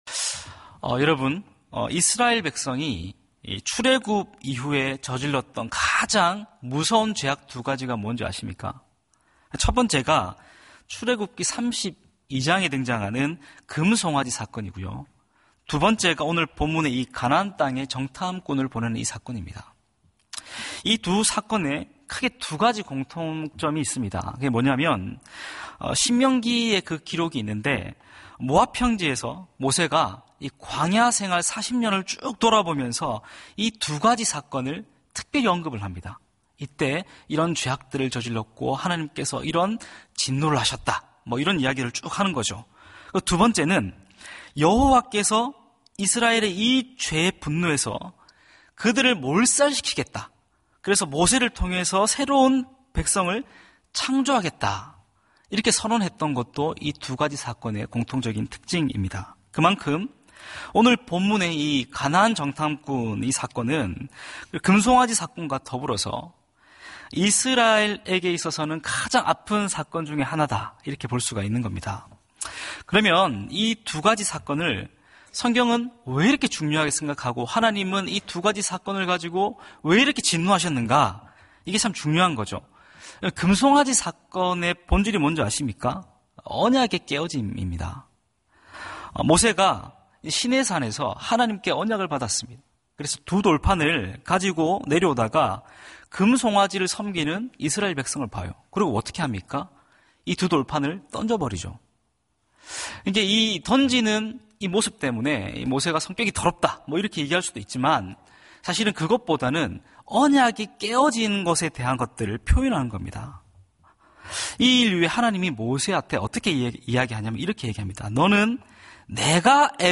예배 새벽예배